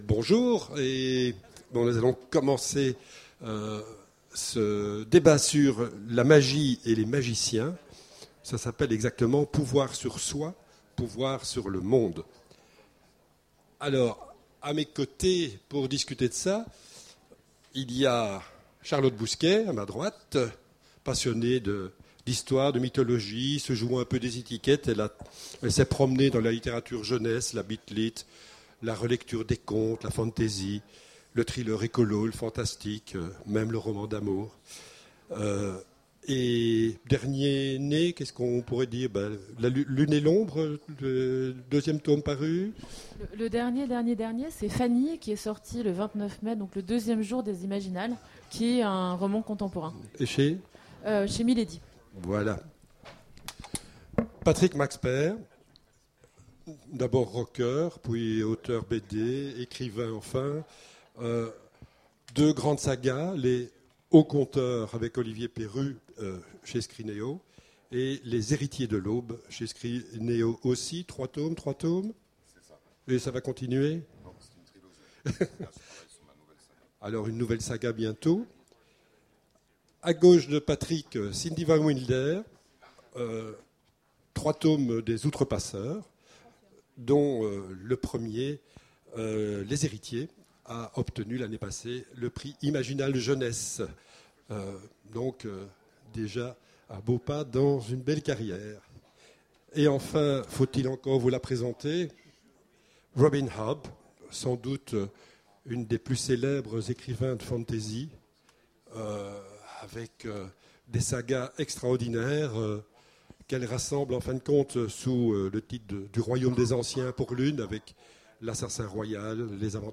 Les Imaginales 2015 : Conférence Magie et magiciens